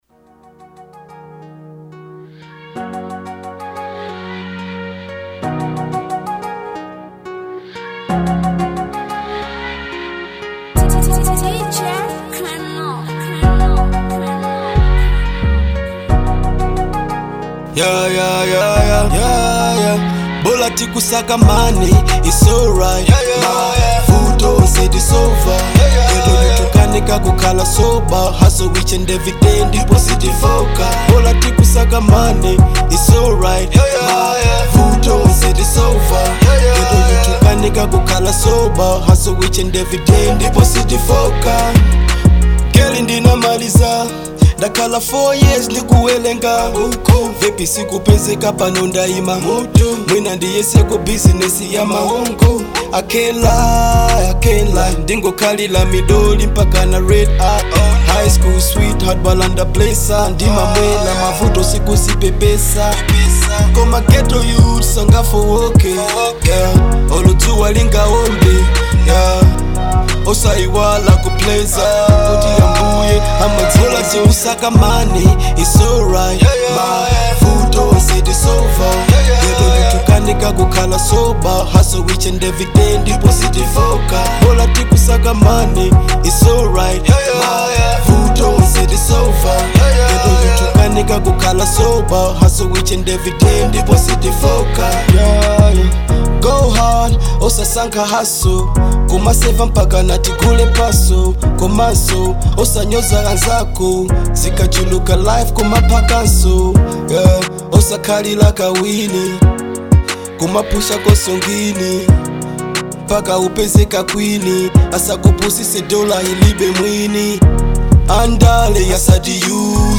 Hiphop